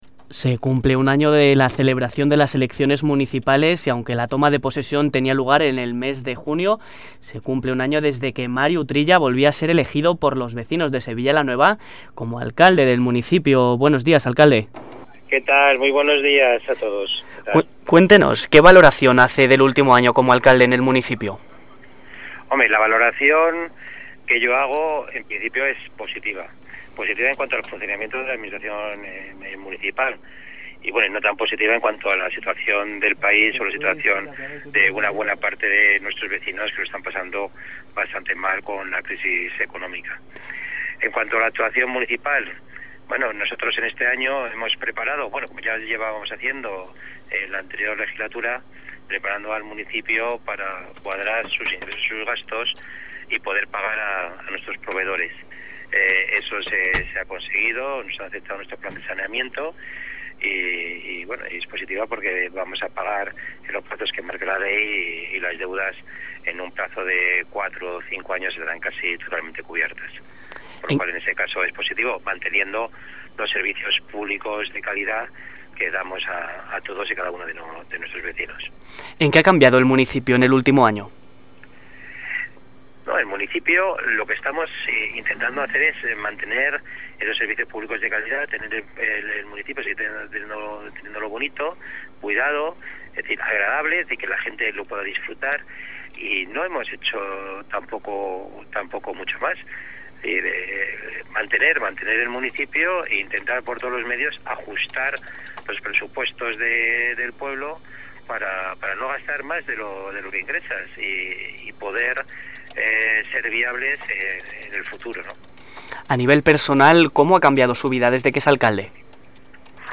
Entrevista exclusiva: Mario Utrilla revela su primer año como alcalde